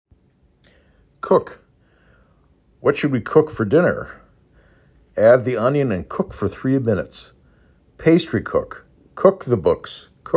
4 Letters, 1 Syllable
k u k